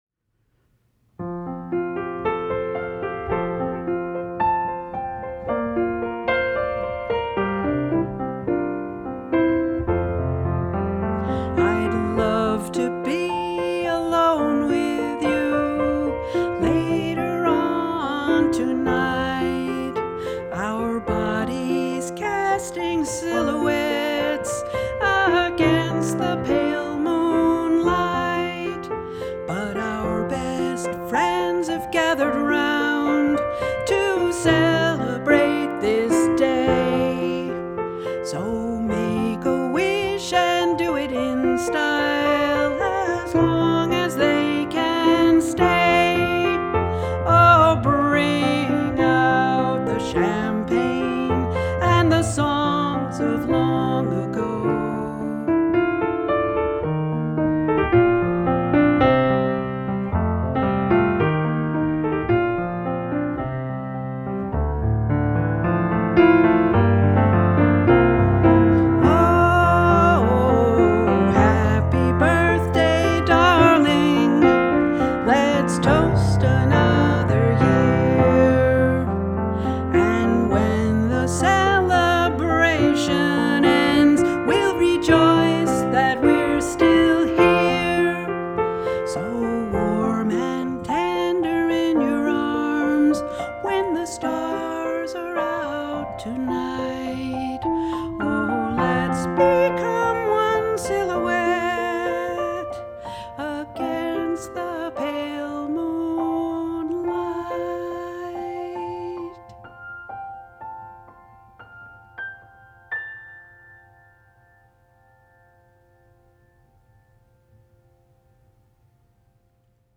Vocal and piano